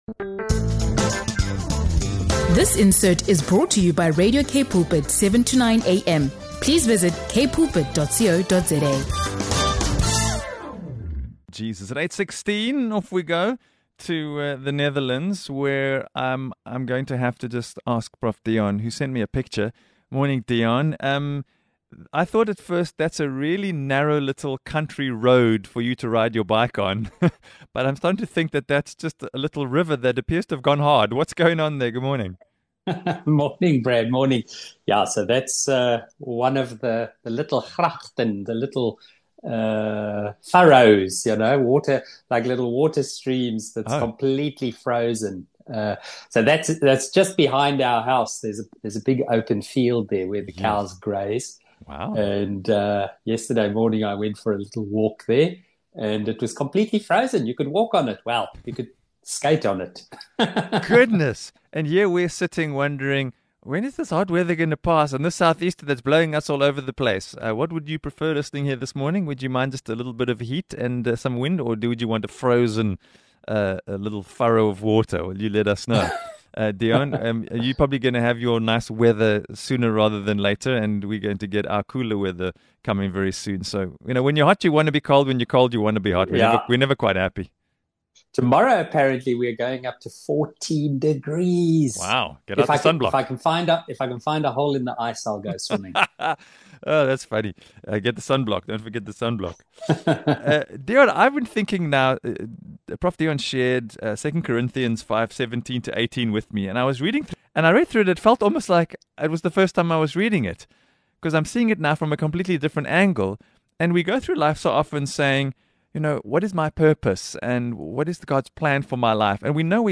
Discovering Your True Purpose: A Thought-Provoking Conversation on Reconciliation and Life's Meaning